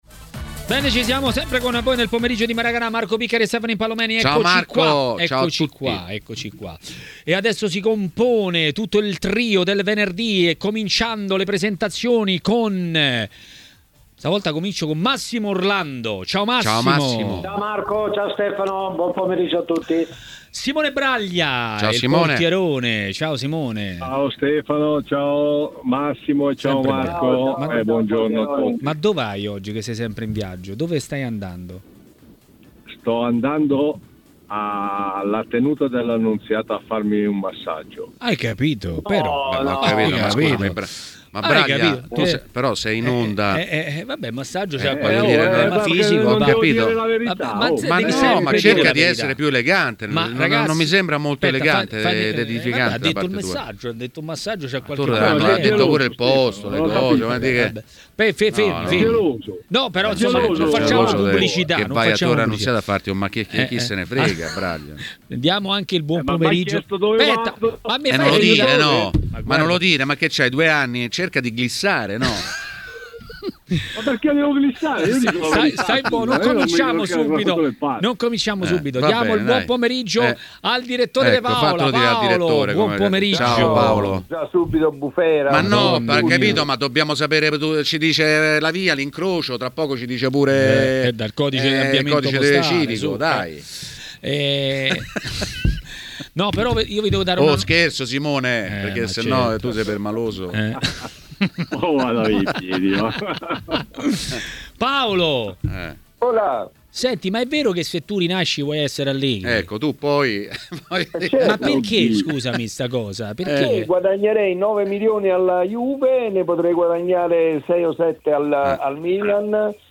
L'ex portiere Simone Braglia è intervenuto a Maracanà, nel pomeriggio di TMW Radio.